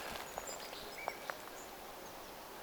hömötiaislintu, 3
homotiaislintu3.mp3